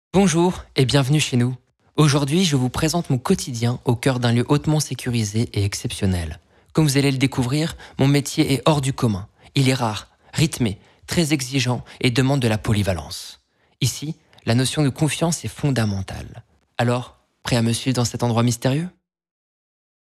Bandes-son
8 - 33 ans - Baryton Ténor